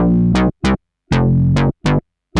/audio/sounds/Extra Packs/musicradar-synth-samples/ARP Odyssey/Arp B Lines/
Arp B Line 02.wav